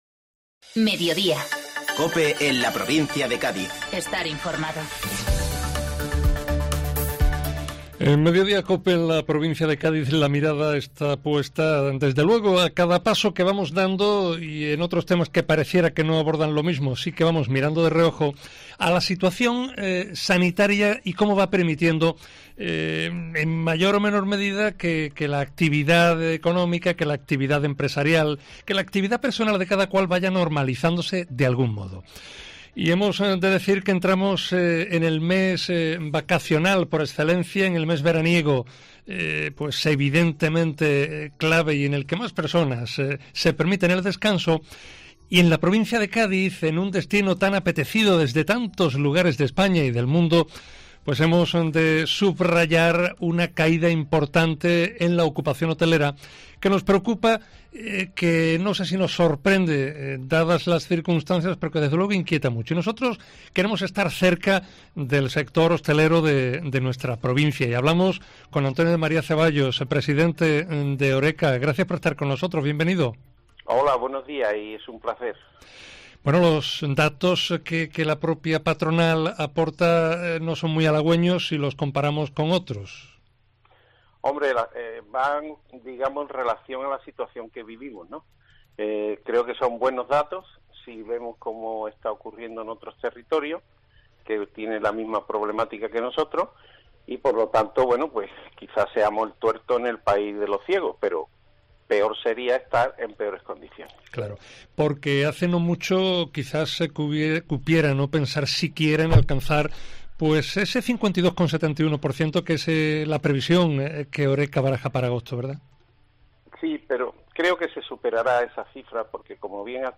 AUDIO: Entrevista en 'Mediodía Cope Provincia de Cádiz'